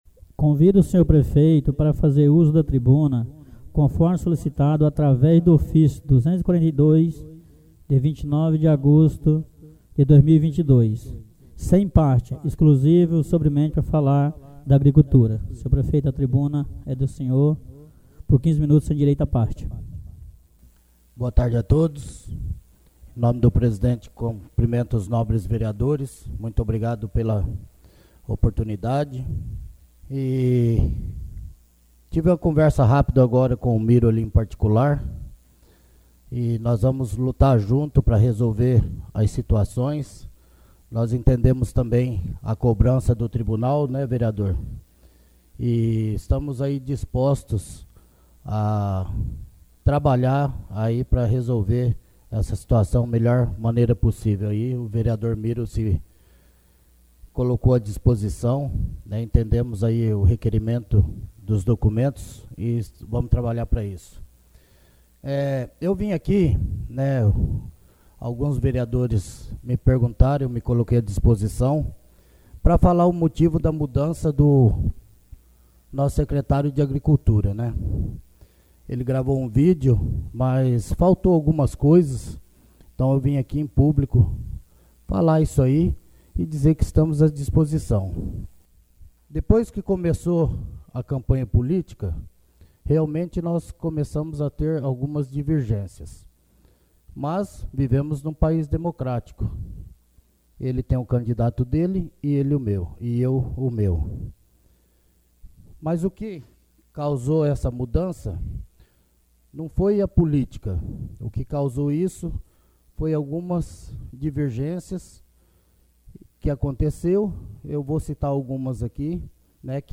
56ª sessão ordinária